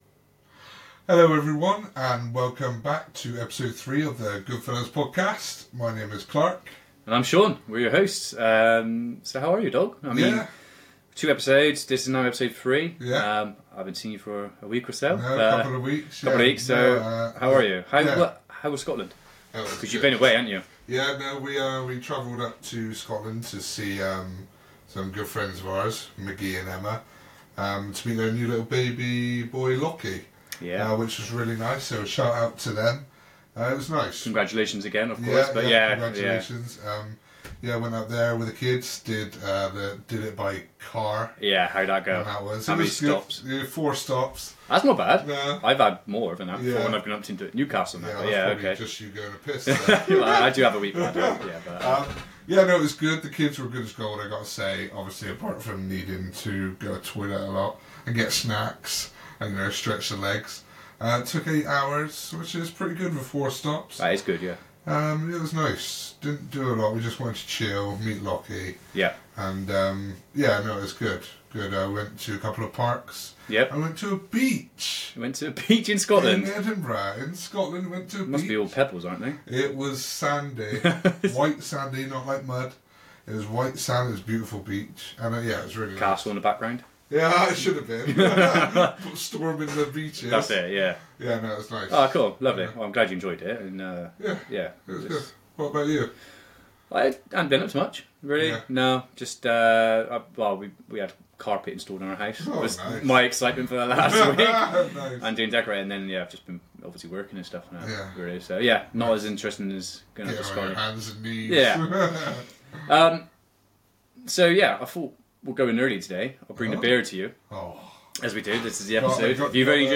We’re also cracking open a new beer, Moosehead, giving our honest thoughts while chatting about meeting up with friends, our best and worst holidays, and of course, a bit of football banter. Strap in, crack a cold one, and join us for a laid-back, laugh-filled ride through our travel experiences.